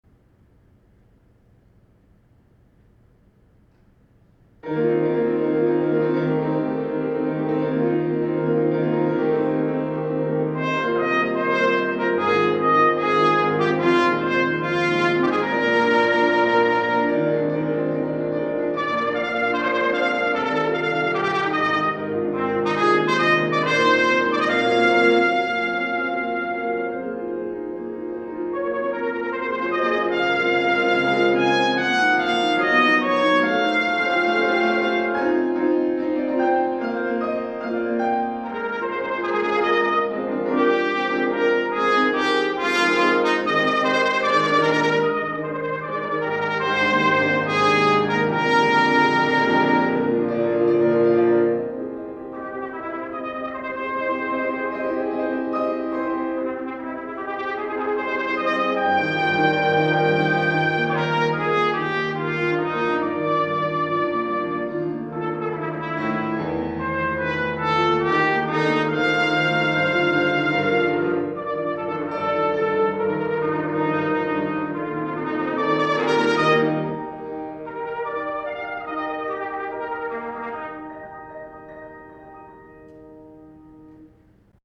III. Allegro Giocoso Part 1